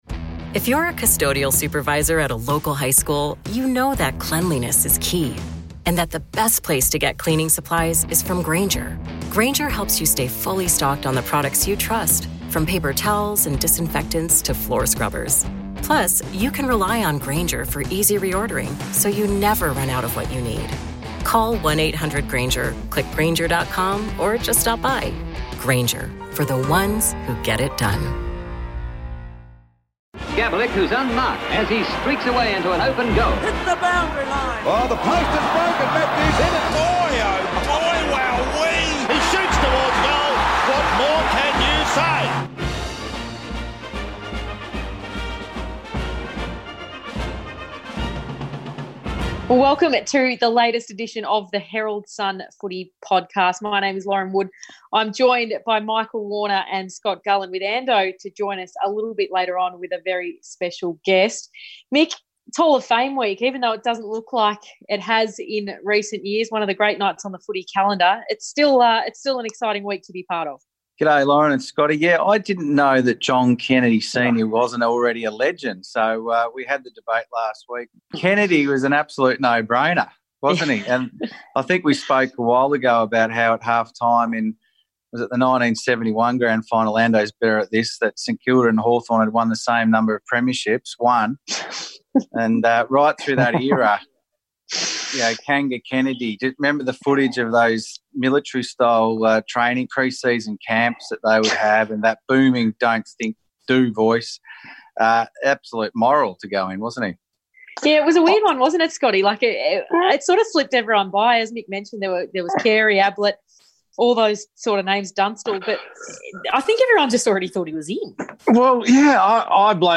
Plus, the panel debate the likely new payout deal, the fake crowd noise and Lance Franklin’s hamstring woes.